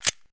pistol_close.ogg